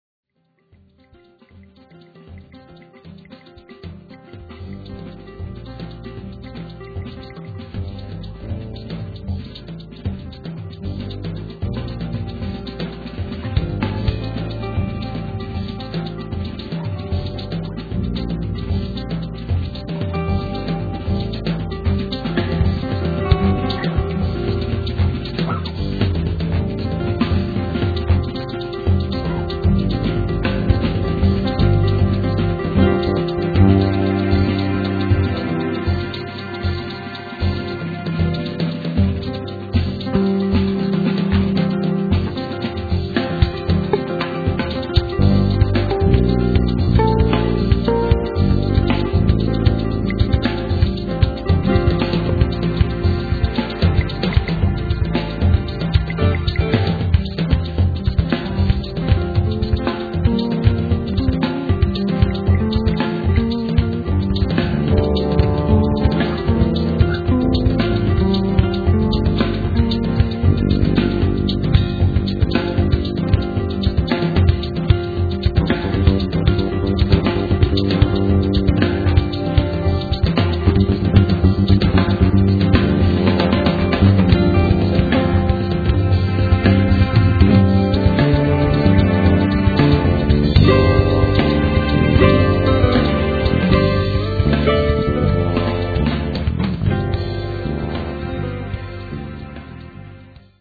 recorded at Midtown Recording